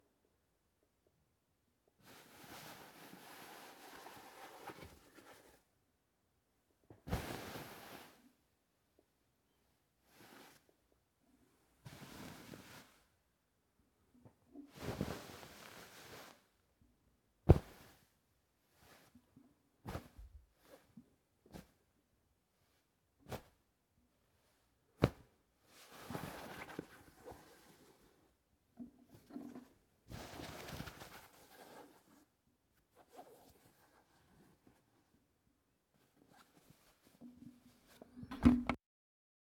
bed sheets moving hitting scratching
bed fabric hitting movement moving noises scratching sheet sound effect free sound royalty free Memes